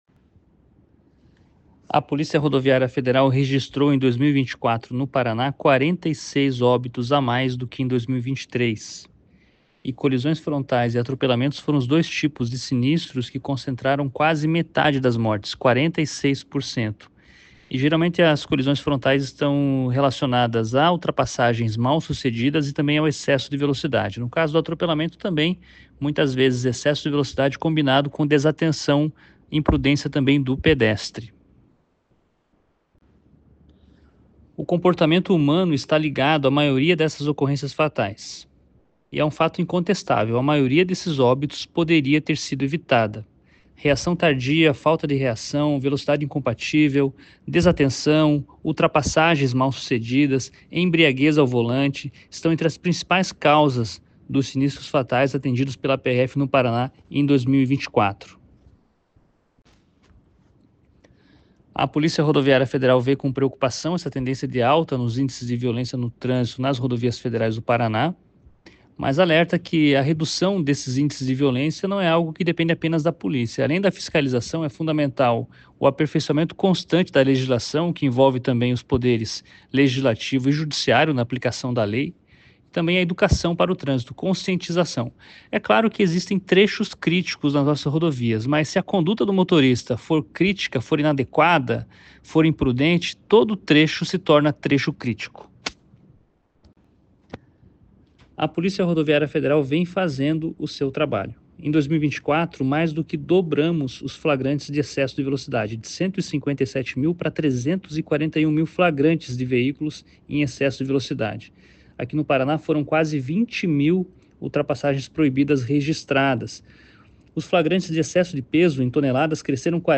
Ouça abaixo o superintendente da PRF Paraná, Fernando César Oliveira, falando sobre o assunto:
sonora_fernando_cesar_oliveira_balan_sinistrosmp3.mp3